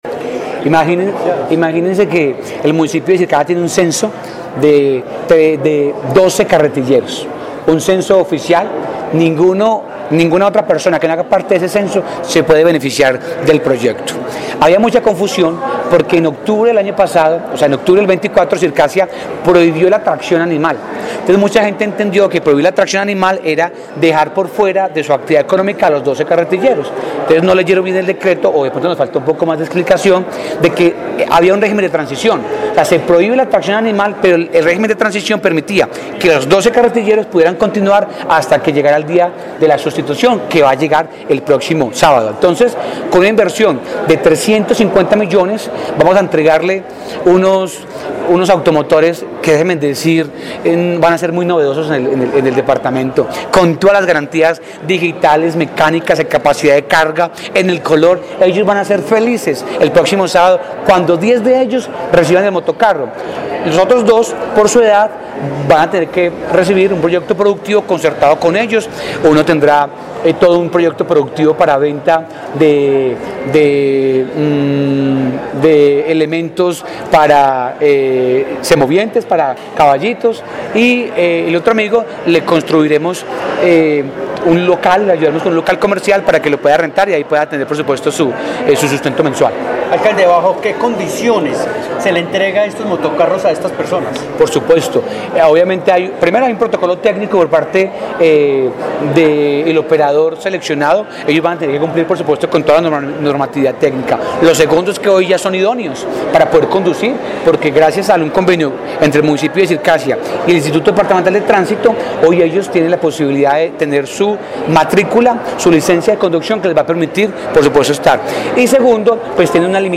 Julián Andrés Peña, alcalde de Circasia, Quindío